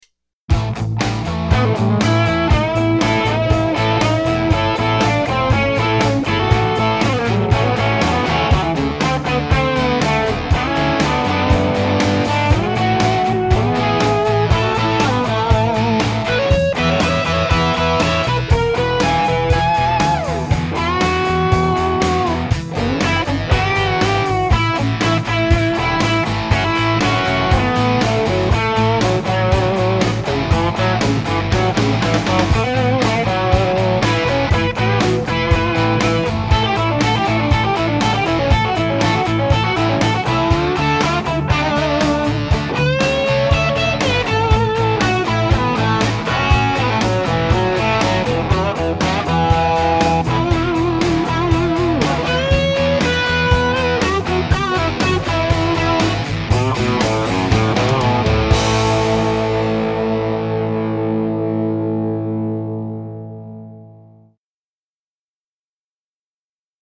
Genre: Country.